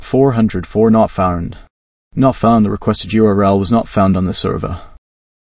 value-investors-tts - a voice model for